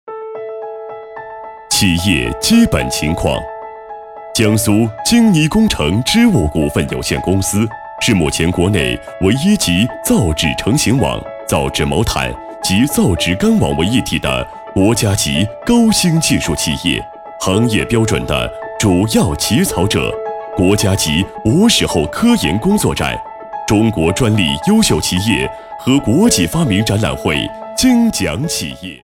A continuación le mostramos un ejemplo de locución con doblaje en chino de un vídeo corporativo  en la presentación de una empresa de provincia Jiangsu, leader de sector en el nivel nacional.
Locutor-varón-chino-22.mp3